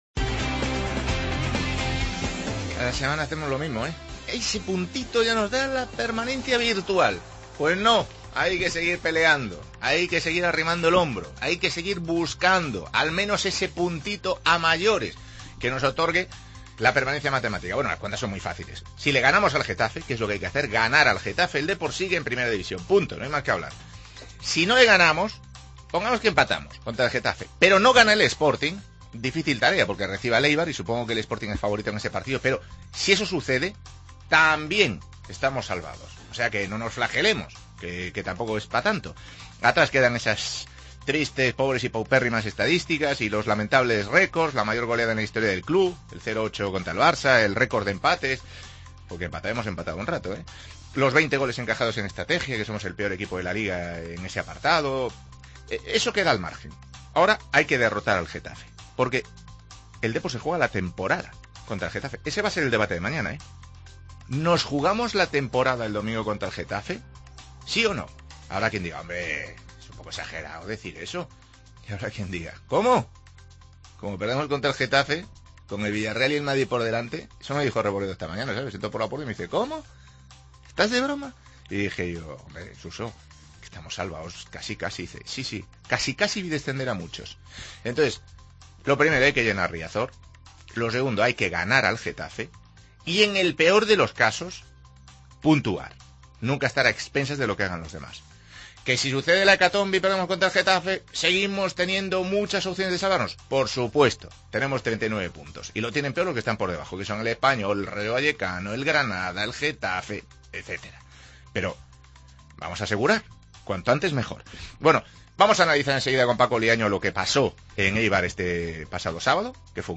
AUDIO: Análisis del Eibar 1 Deportivo 1. Escuchamos a Fede Cartabia, autor del gol en Ipurua